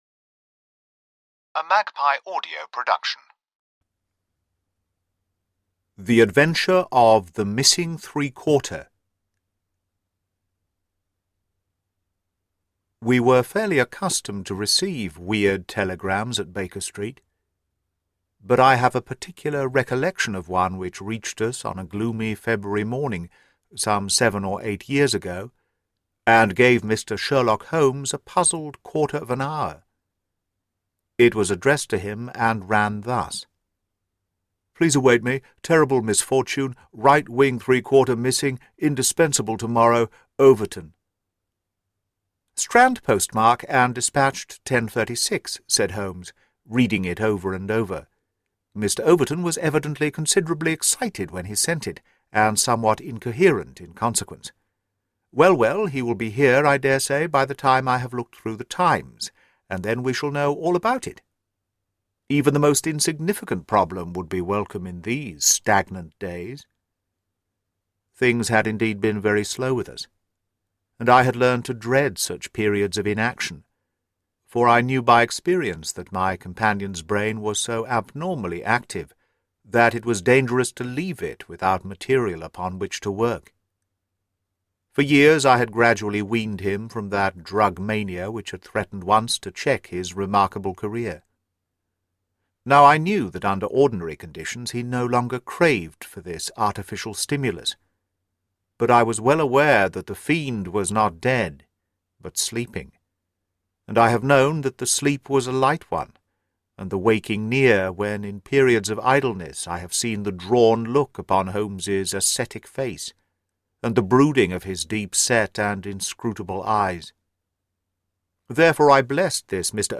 The Dancing Men: Conan Doyle’s Most Intriguing Code (Audiobook)